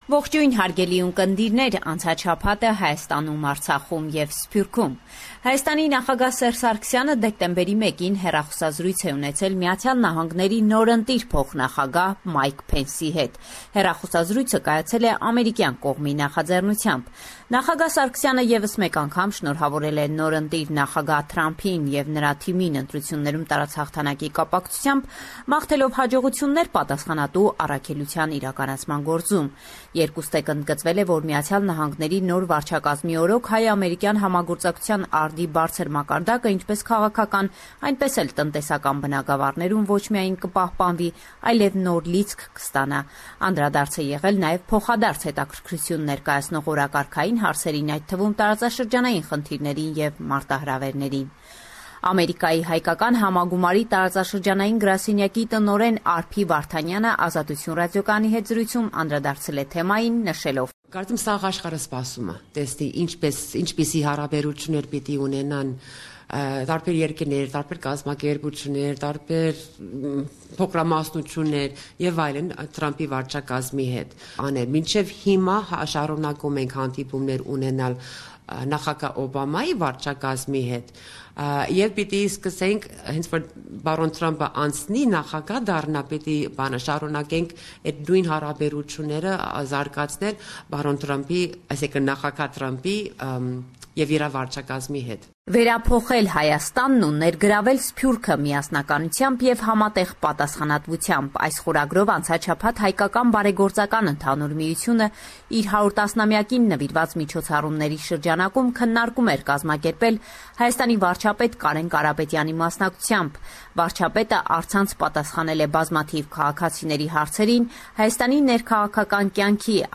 Latest News – 6 December 2016